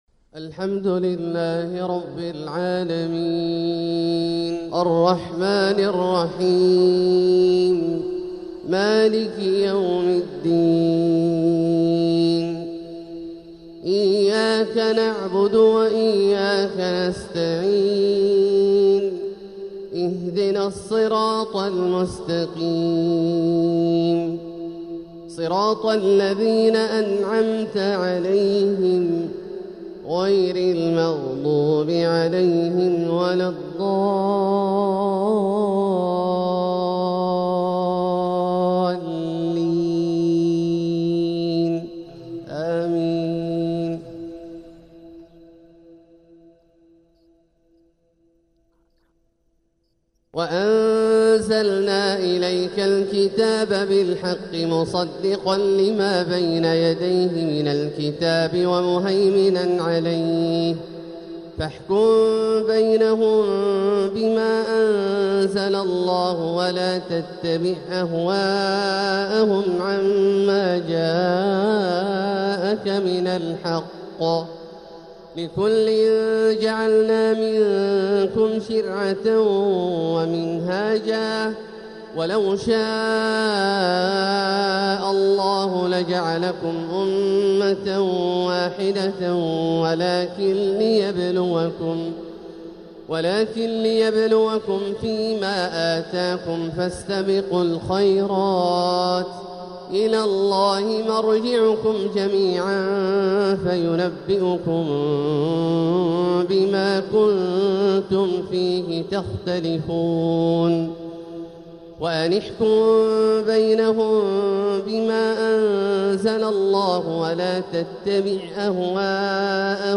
تلاوة من سورة المائدة 48-66 | فجر الثلاثاء 8 ربيع الآخر 1447هـ > ١٤٤٧هـ > الفروض - تلاوات عبدالله الجهني